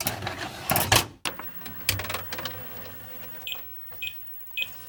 weaponsafe1.ogg